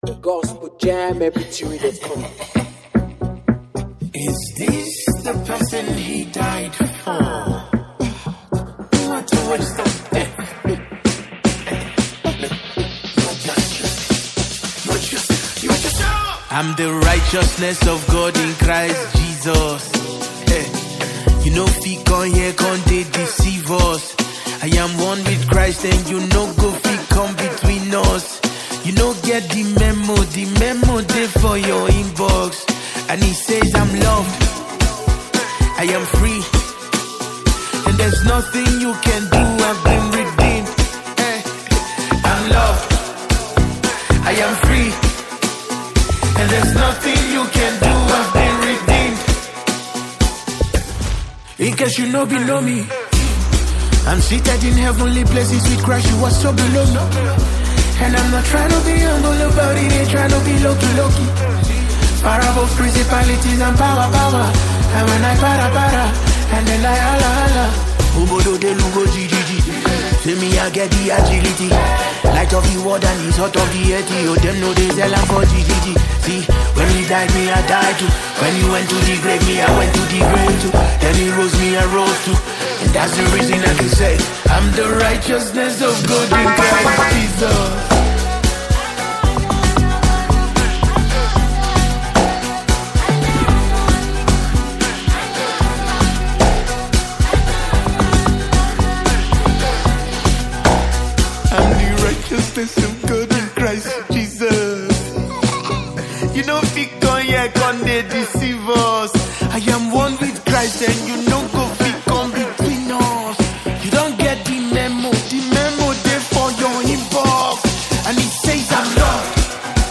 gospel song
a blend of Afro-gospel and worship